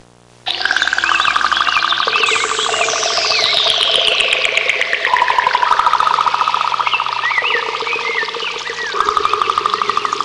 Digital Wilderness Intro Sound Effect
Download a high-quality digital wilderness intro sound effect.